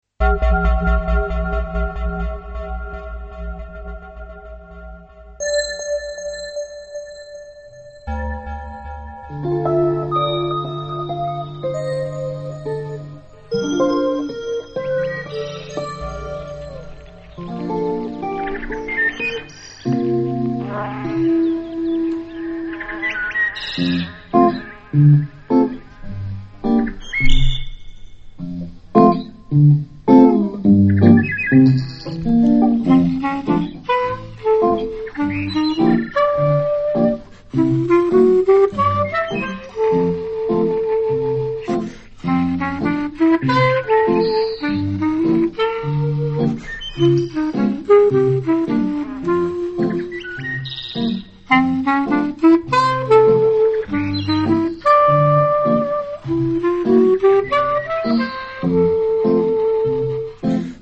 Аудиокнига Музыка гор | Библиотека аудиокниг